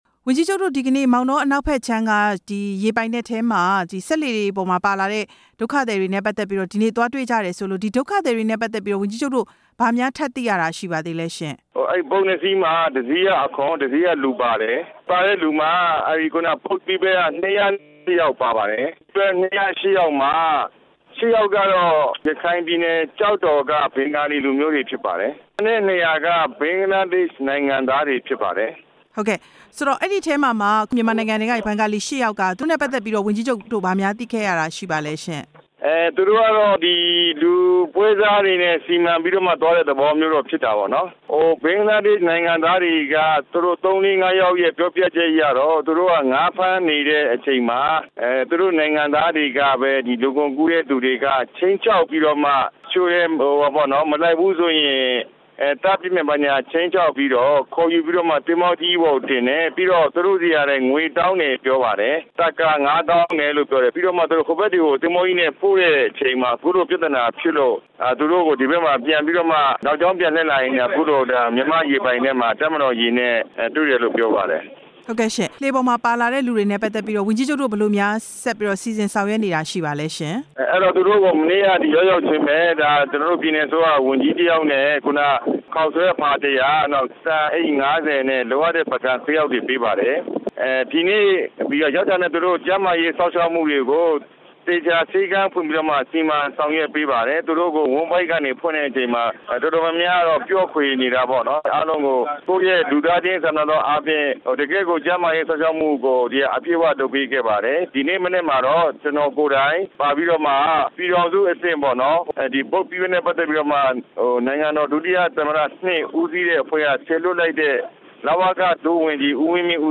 ဝန်ကြီးချုပ် ဦးမောင်မောင်အုန်းနဲ့ မေးမြန်းချက်